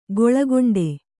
♪ goḷagoṇḍe